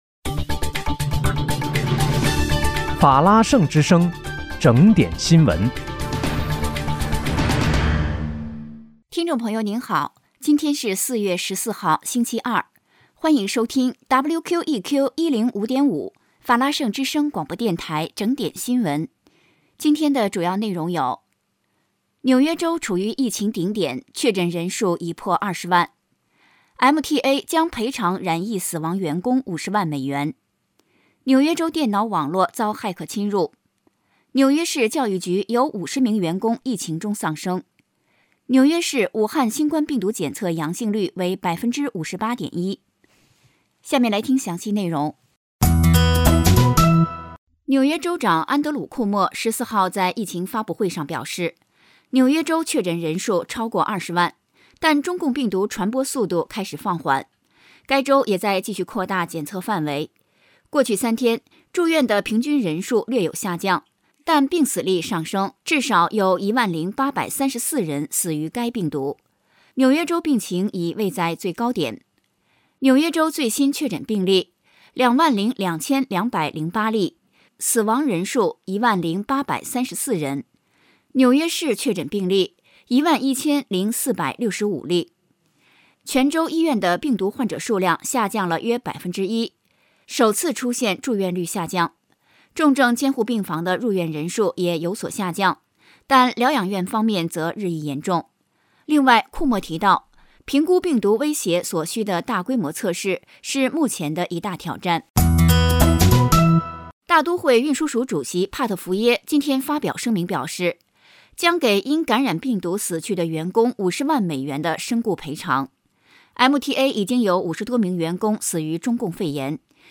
4月14日（星期二）纽约整点新闻